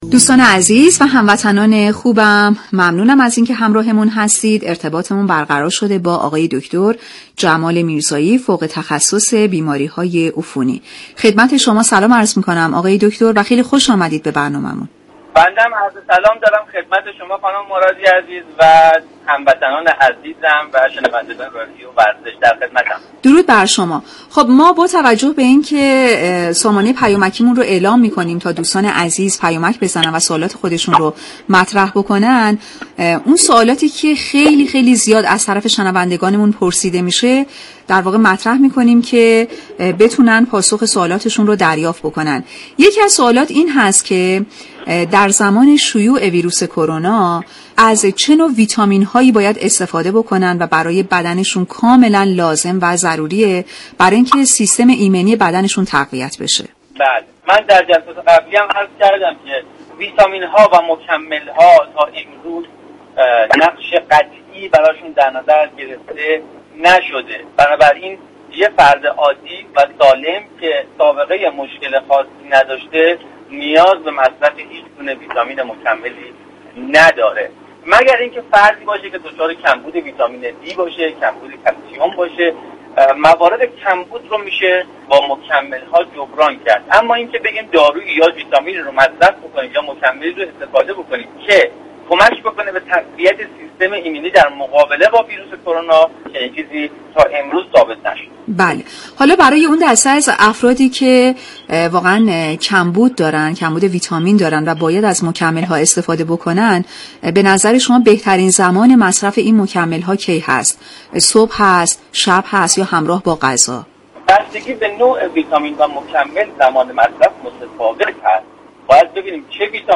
این برنامه ساعت 8:30 هر روز به مدت 30 دقیقه از شبكه رادیویی ورزش تقدیم شنوندگان می شود.